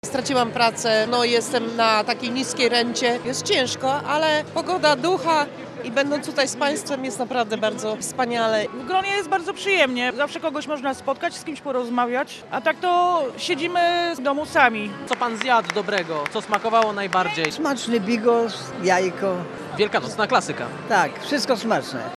Z uczestnikami spotkania rozmawiał nasz reporter.